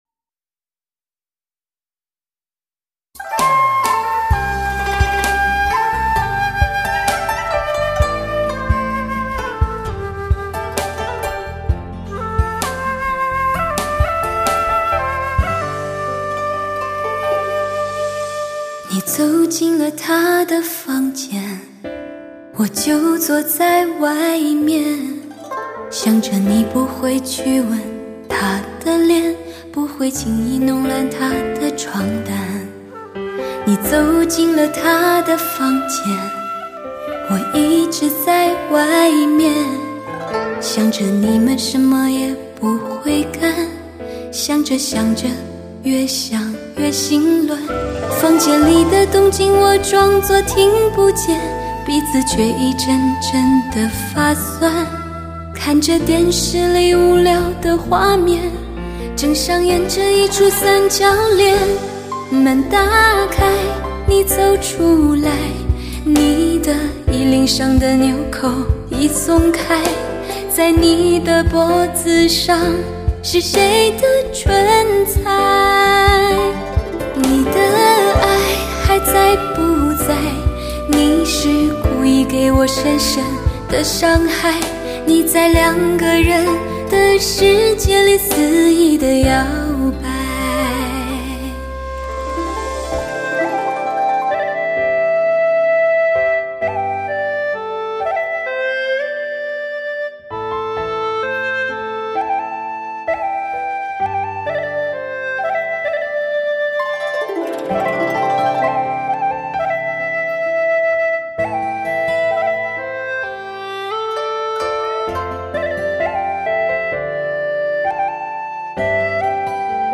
音乐类型: HI-FI流行音乐/Pop /人声
新一代黑胶高保真珍藏CD，HI-FI音响专业测试发烧极品。
让人心疼到发抖的声音，拥有不能抵挡的诱惑，让人醉心的享受。